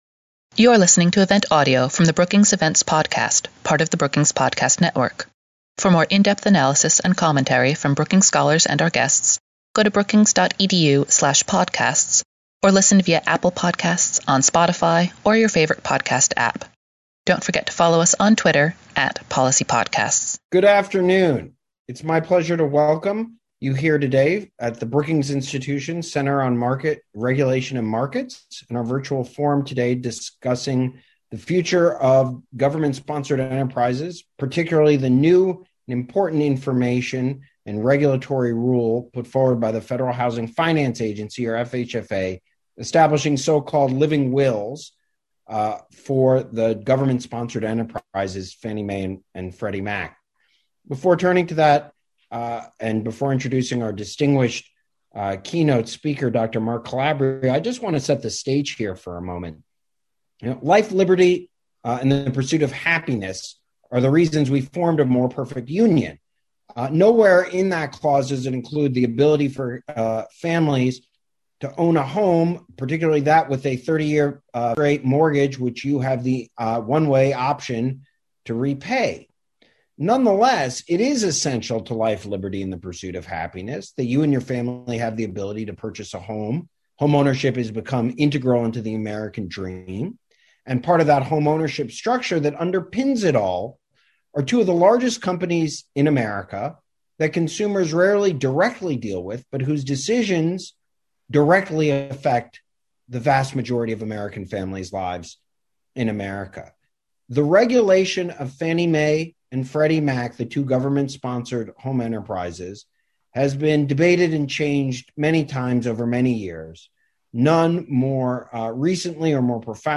On Tuesday, May 11, the Center on Regulations and Markets at Brookings will host Mark Calabria, director of the FHFA, to give keynote remarks on the agency’s progress in creating this rule. Following the keynote remarks, a panel of experts will respond to the resolution plans.